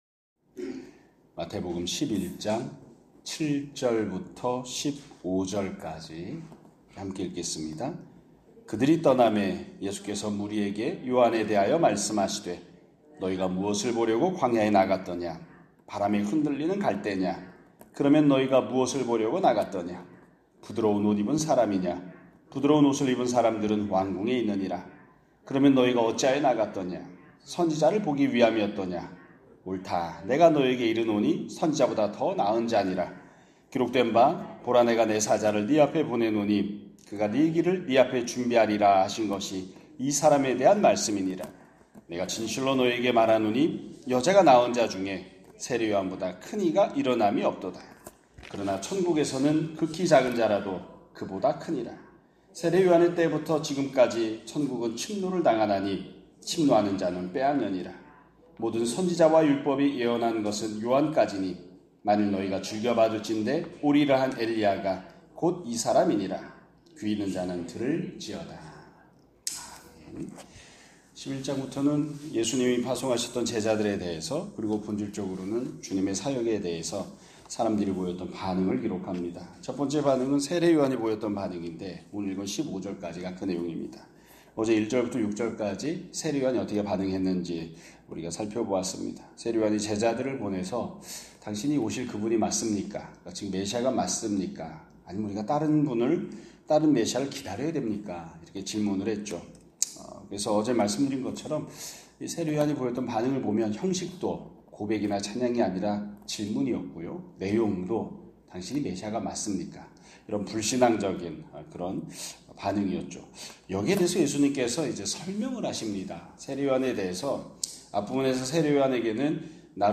2025년 8월 26일 (화요일) <아침예배> 설교입니다.